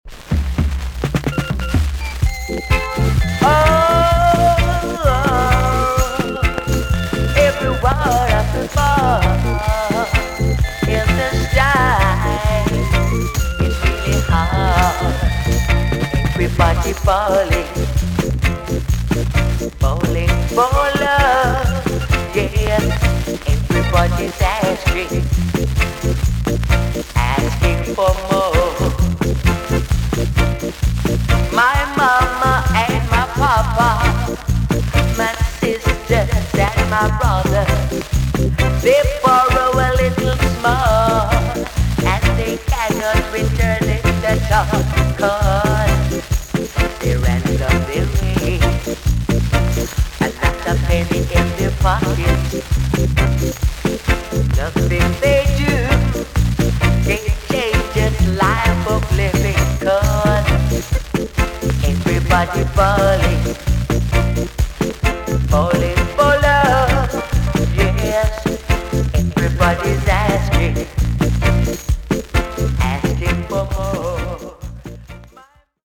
TOP >REGGAE & ROOTS
VG ok 全体的にヒスノイズが入ります。
1971 , NICE ROOTS TUNE!!